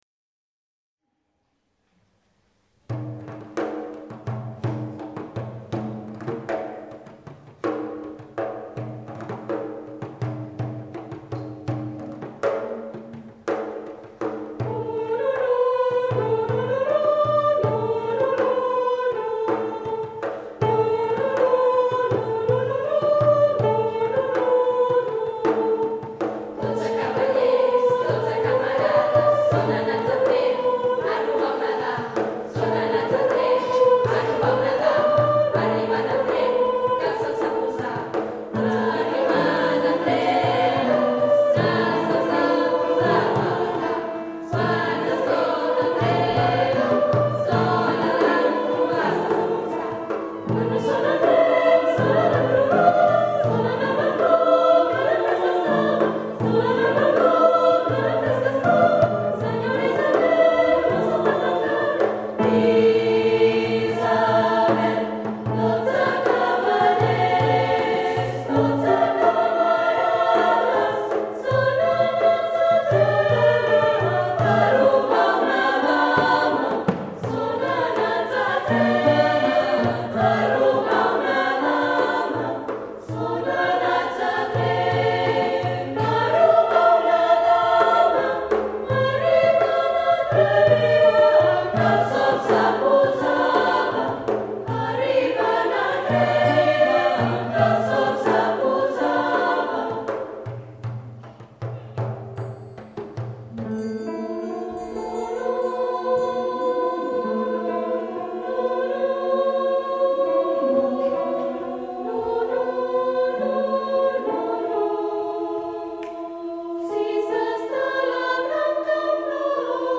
Instrumentos:Coro SATB